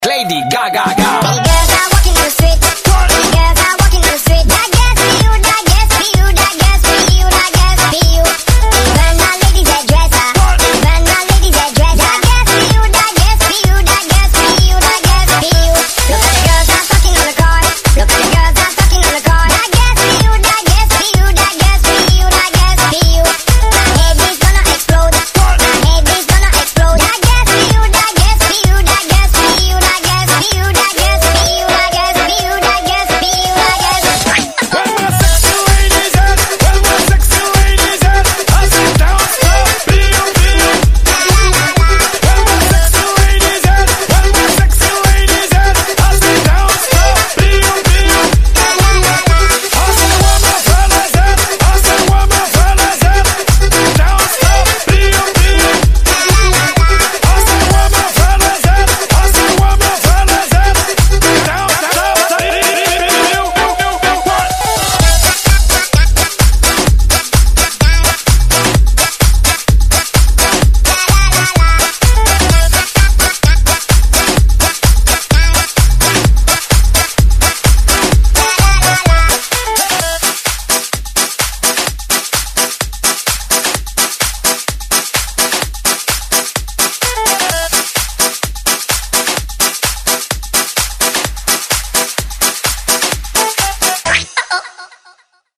Прикольная заводная песенка